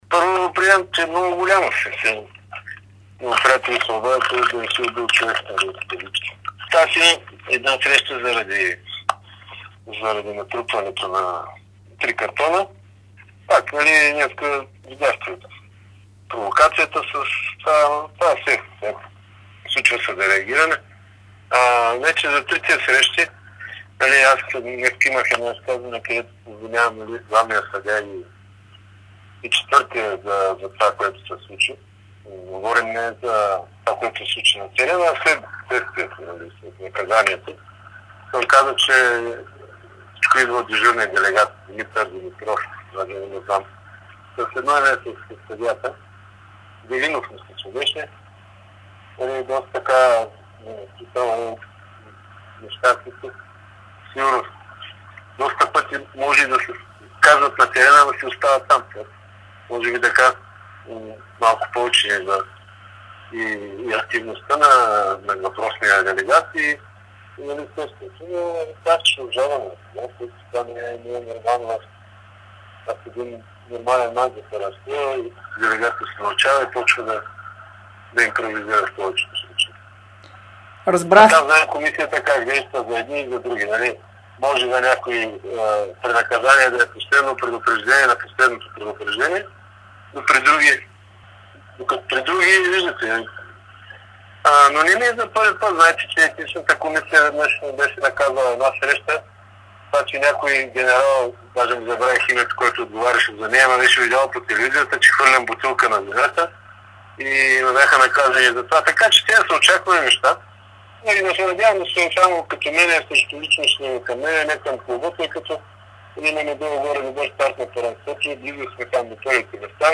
Старши треньорът на Черно море Илиан Илиев коментира пред Дарик радио и dsport обстоятелствата за наказанието от четири мача, което ДК към БФС стовари върху него.